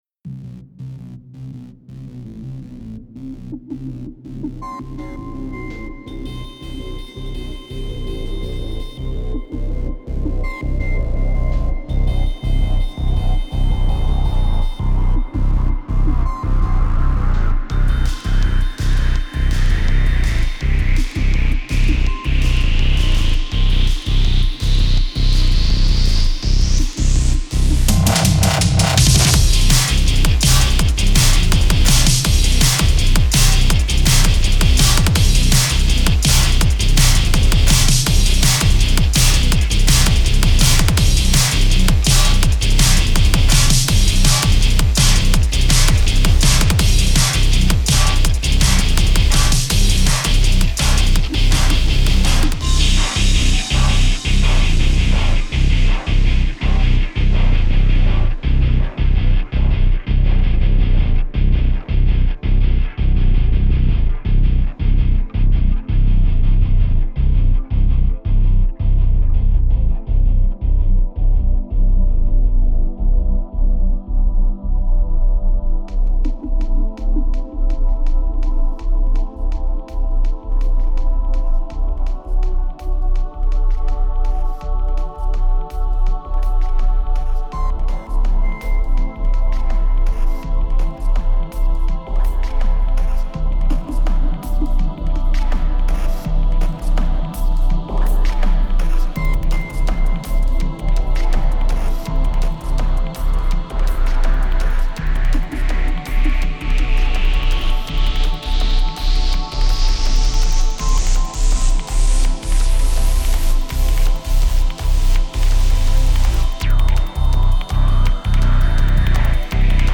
Collaboration: "Elektrobräter" (Metal, D&B, Electro-Industrial, Experimentell)
das ganze bildet genretechnisch ein schön buntes gemisch aus allerlei einflüssen.
das ganze ist auch etwas vorsichtiger komprimiert, wollte ja auch ein bisschen dynamik erhalten.